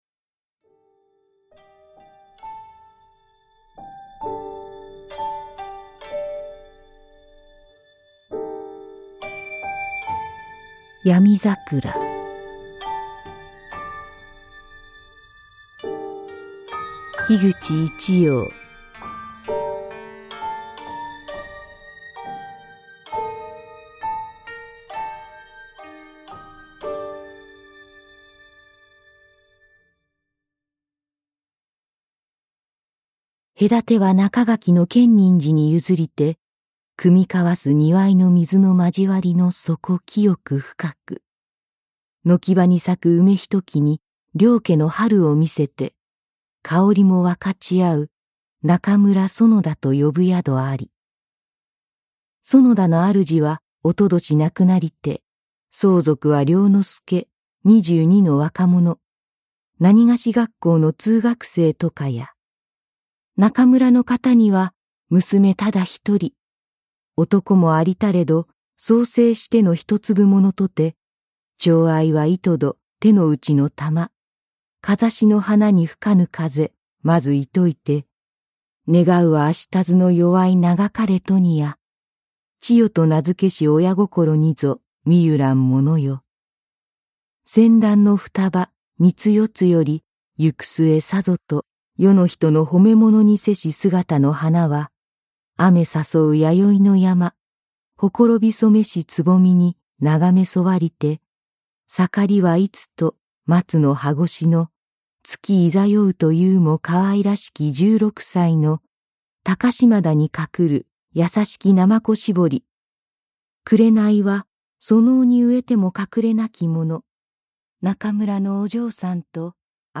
[オーディオブック] 闇桜